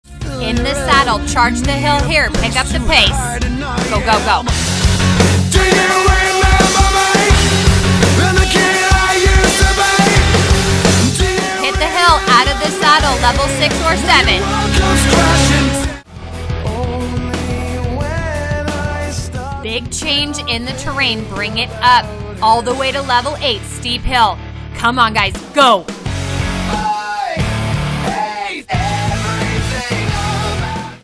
The playlist includes music from The Beastie Boys, Three Days Grace and Awolnation. Work through hills, sprints and endurance intervals.